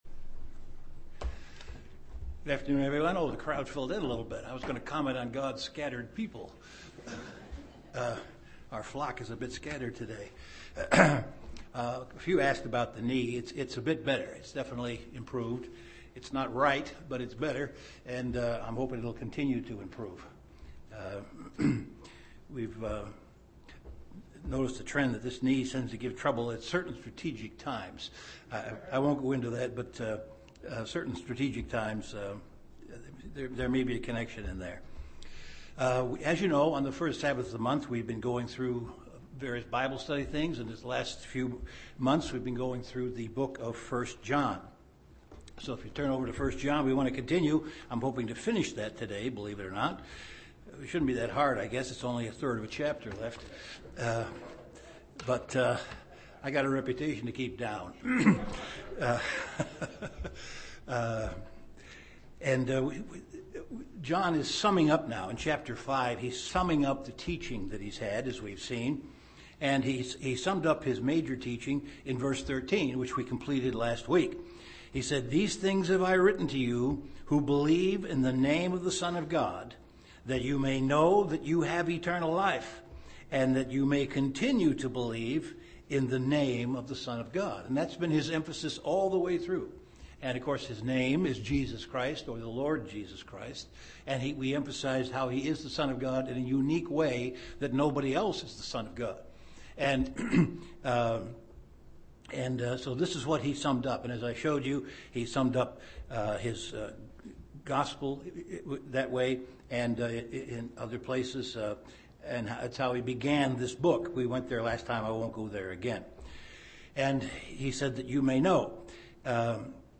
The final session of an in-depth Bible study on the book of 1 John.